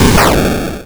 bakuhatu87.wav